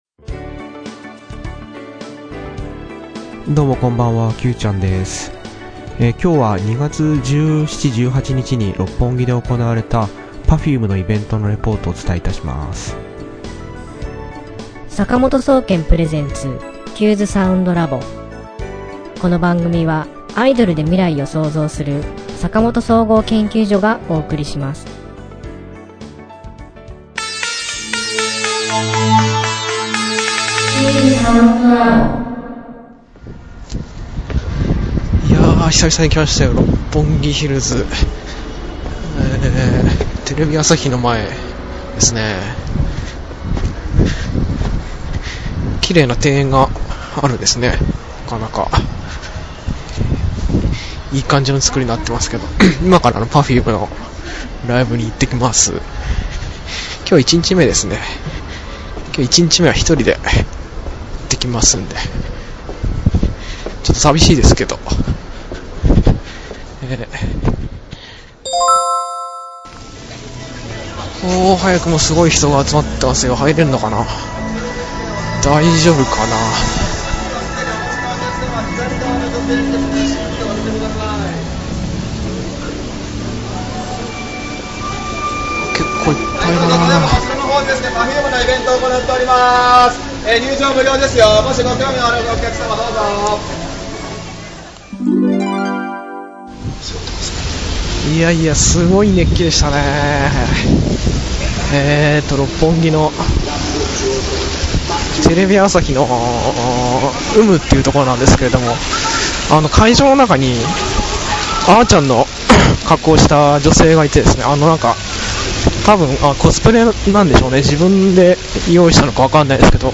今回のイベントレポートはまたまたポッドキャスティング（音声）でお送りいたします。＜注＞イベントの制約によりイベント最中の音声は収録されていませんのであらかじめご了承ください。